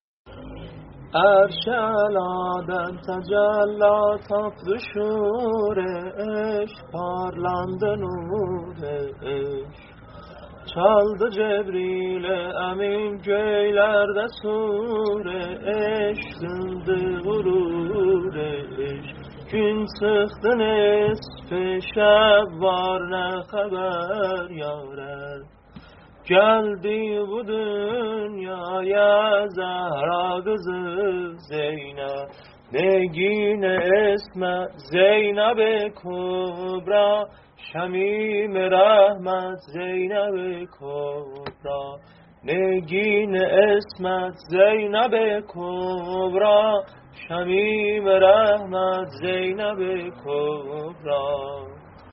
میلادیه